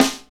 Index of /90_sSampleCDs/Northstar - Drumscapes Roland/KIT_Hip-Hop Kits/KIT_Rap Kit 1 x
SNR H H S06L.wav